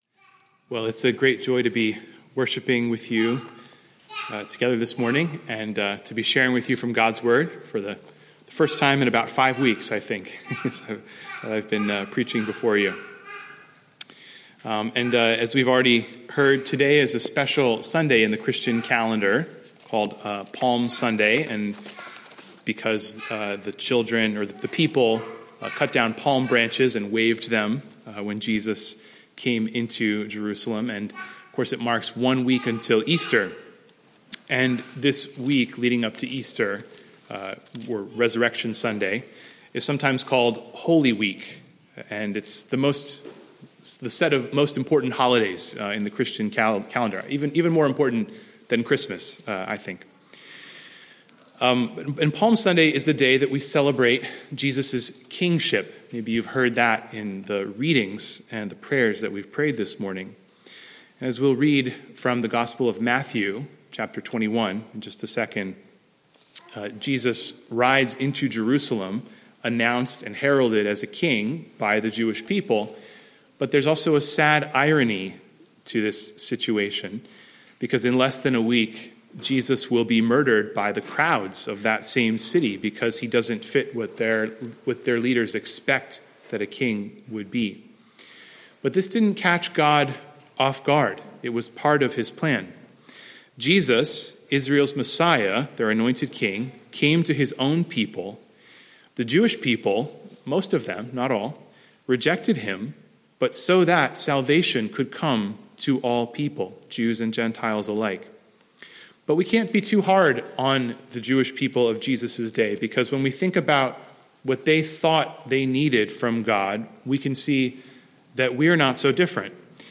Palm Sunday
Sermon Text: Matthew 21:1–11